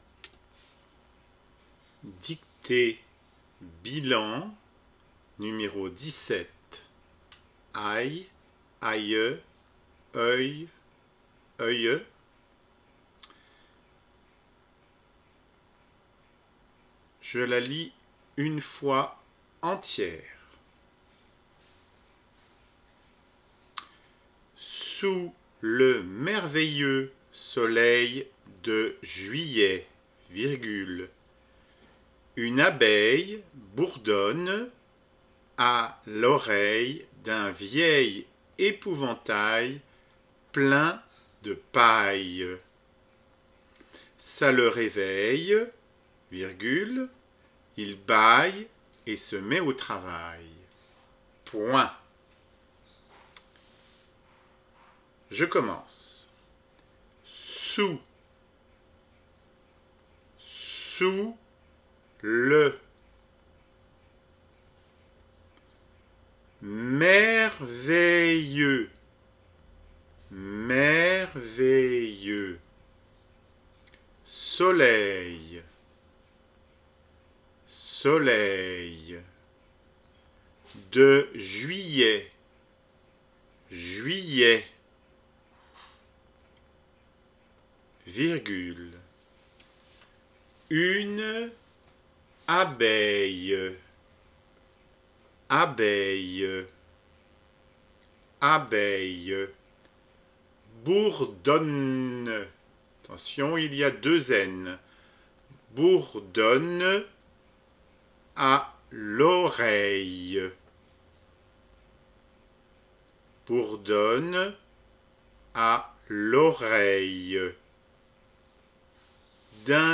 Tu fais la dictée en marquant des arrêts si tu veux.
dictee_bilan_17.mp3